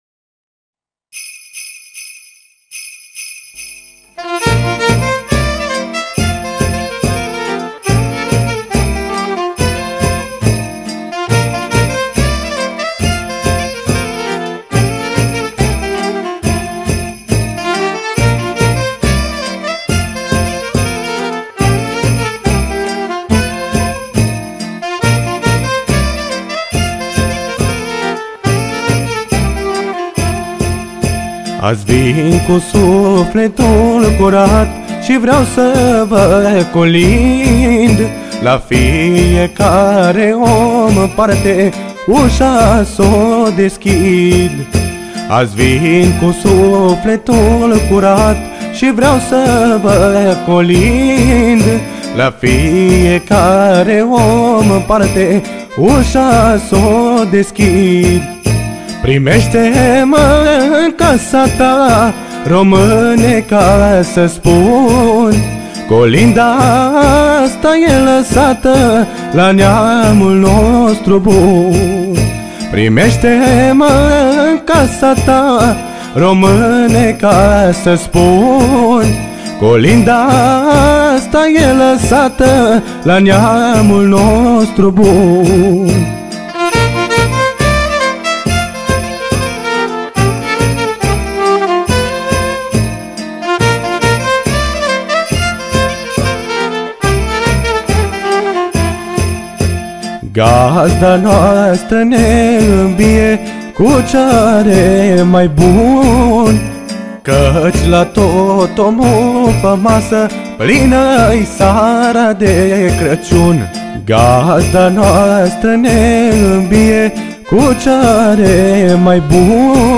Data: 02.10.2024  Colinde Craciun Hits: 0